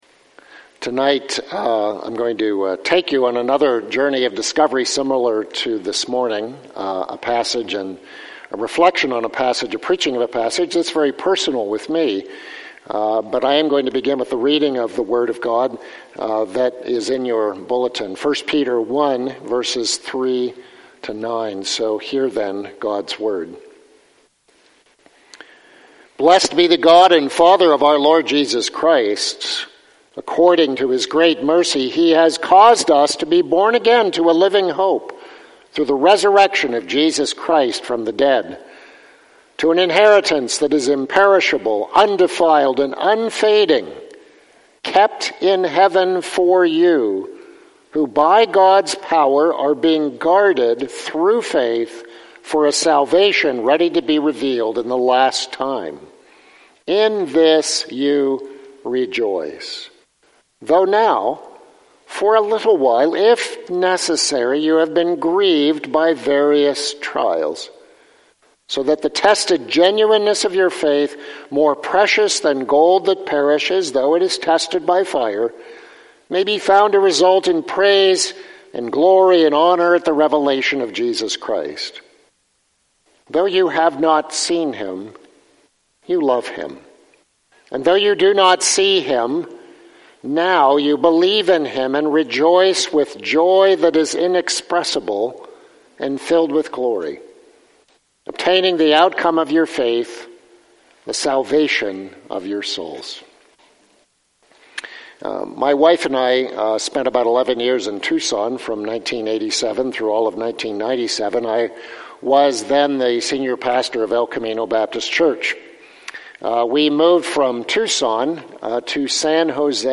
(guest preacher)